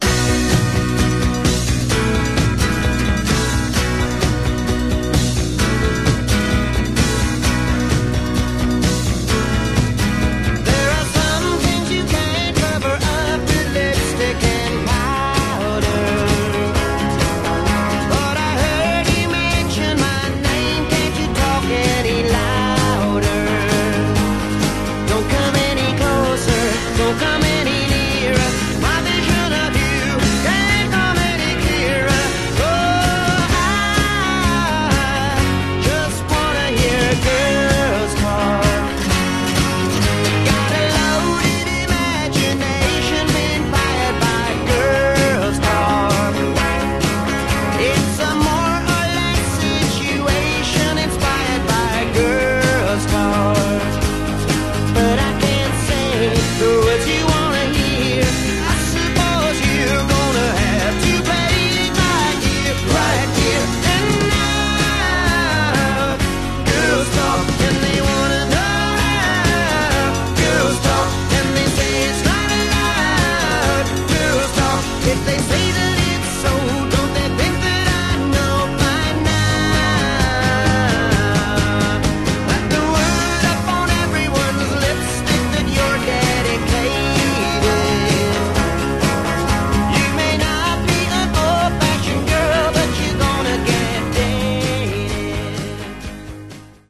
Genre: Power Pop